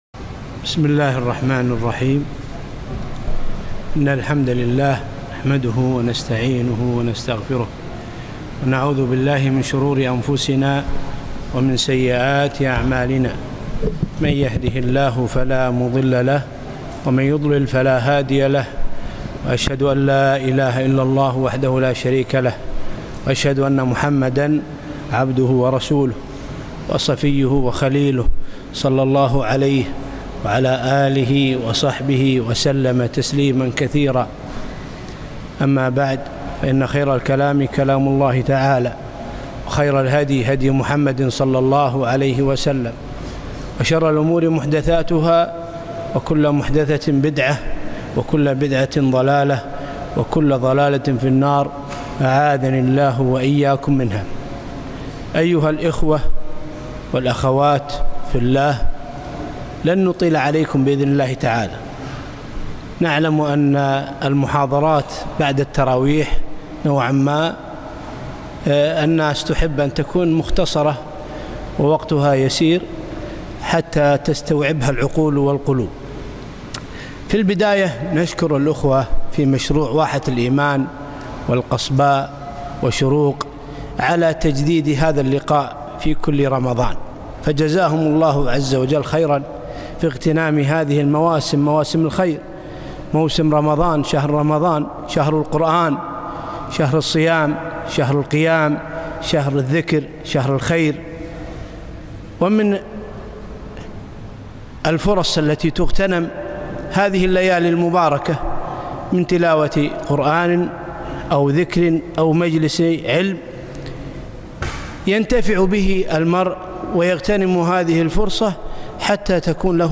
محاضرة - محبة النبي صلى الله عليه وسلم